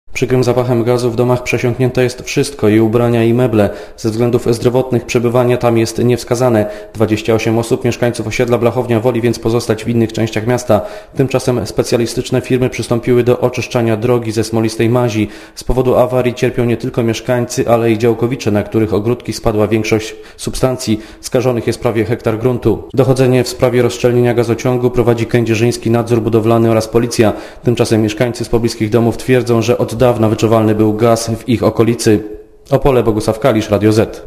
Na miejscu był reporter Radia Zet